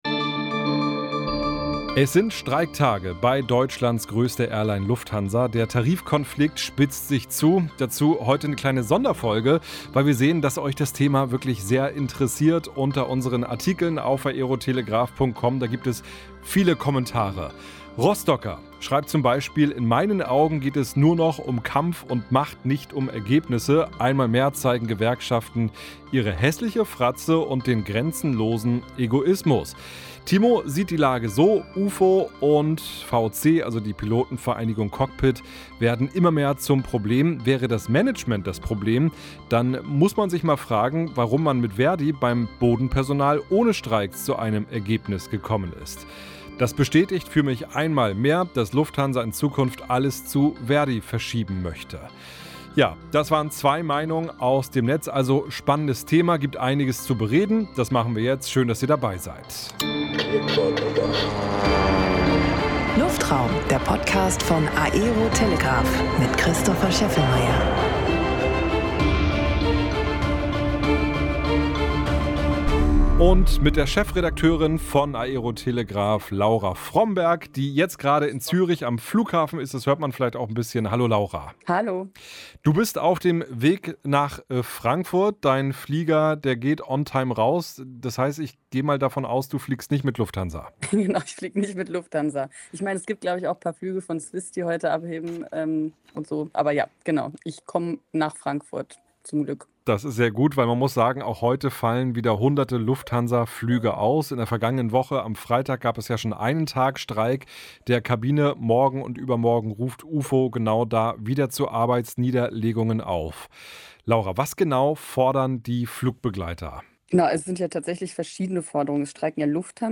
In dieser Folge spreche ich über die aktuelle Situation bei Lufthansa. Im Talk